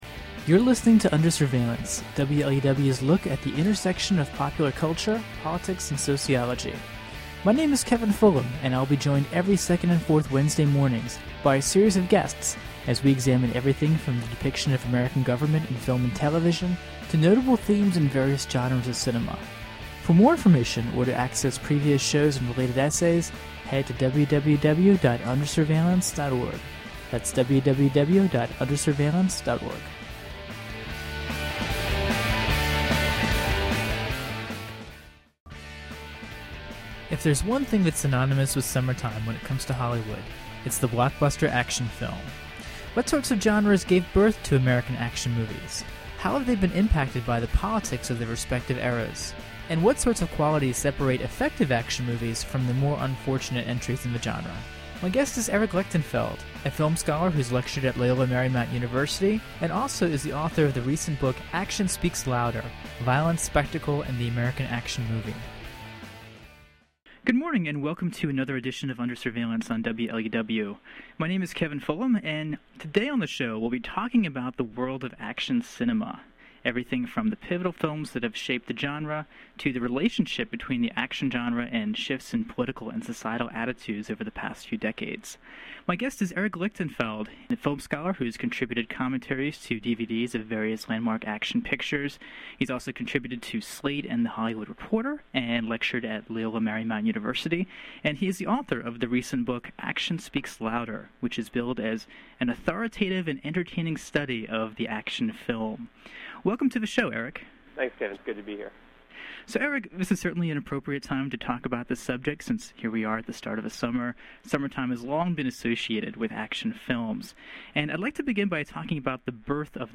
[Originally broadcast on WLUW’s Under Surveillance in July 2009.]